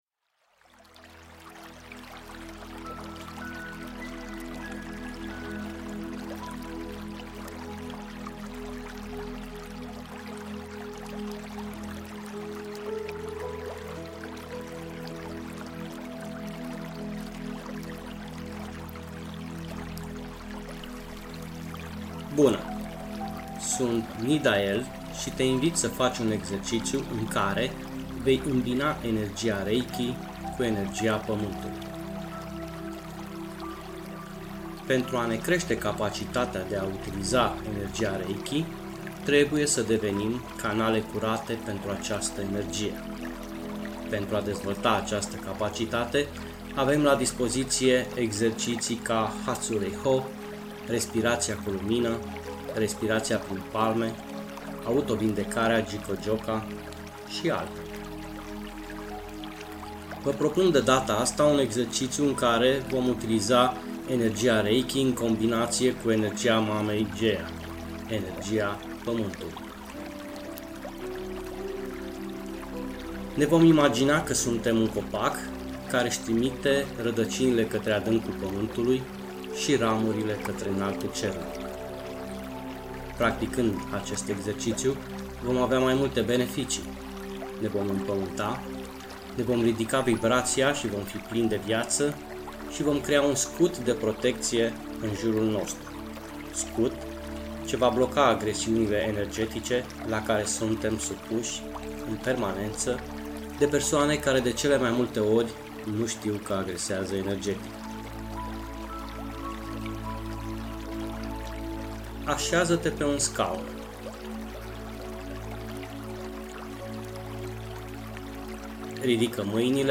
Meditatie ghidata Fii una cu reiki